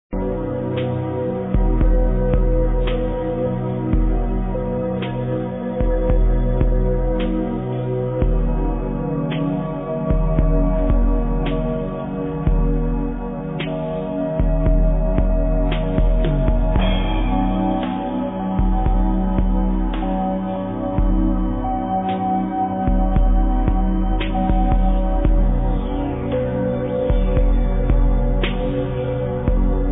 muzyka elektroniczna, ambient
przeszyty kroplistym, pulsującym ostinatem